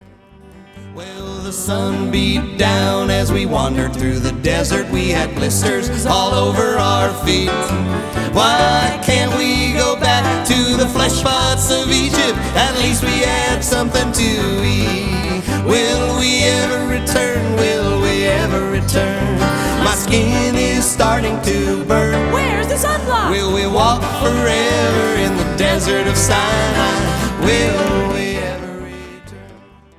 recorded with over 300 friends and fans!